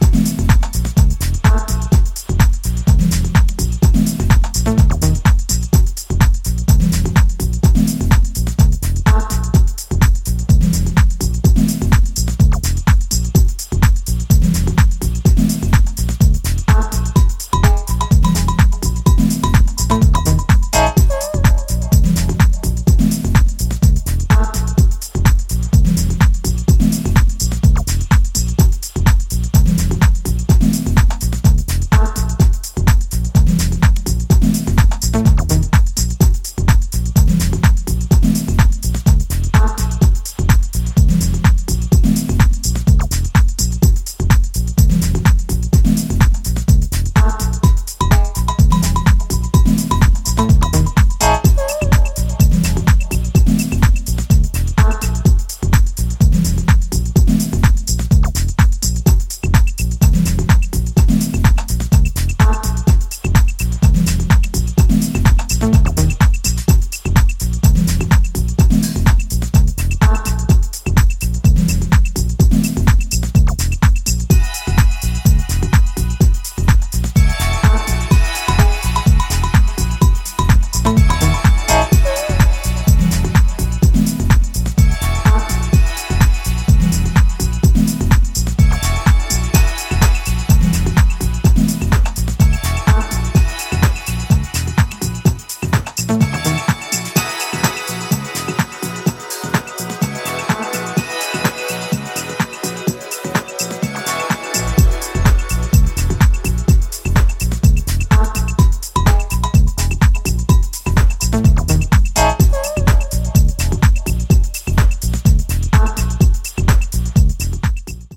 ダビーで陰鬱なムードから静かな高揚を煽っていく